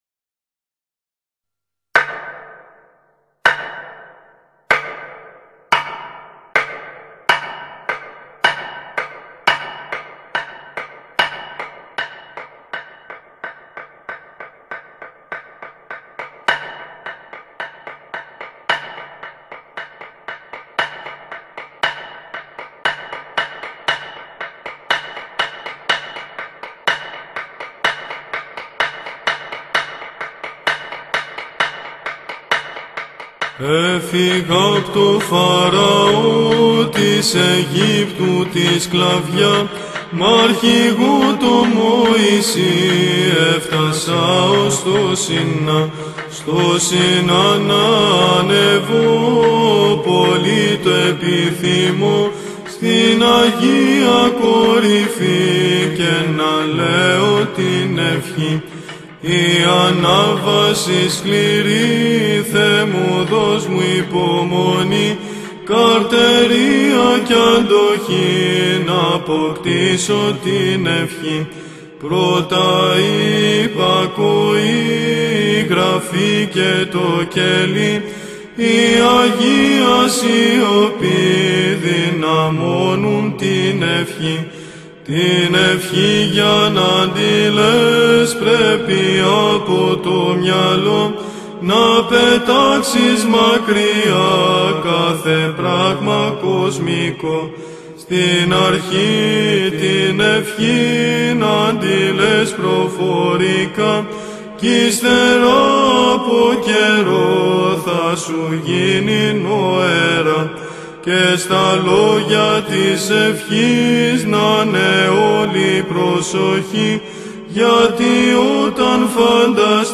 Ψαλτοτράγουδο για την Νοερά Προσευχή